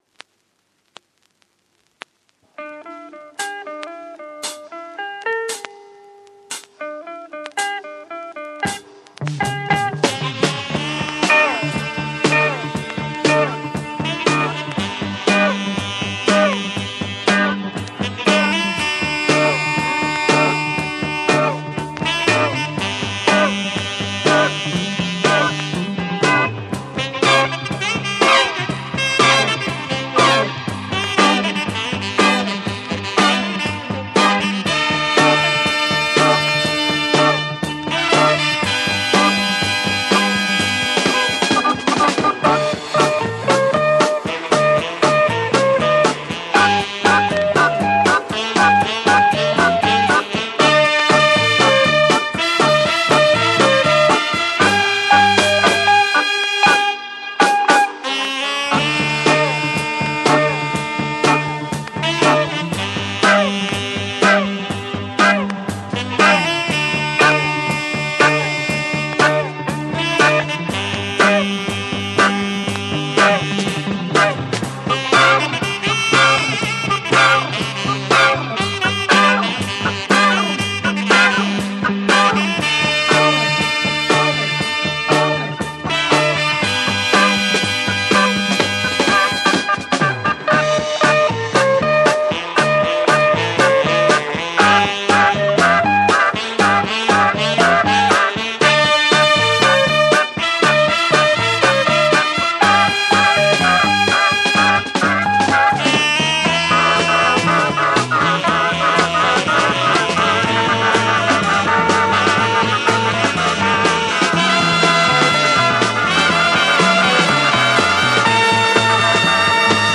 Organ Mod dancer album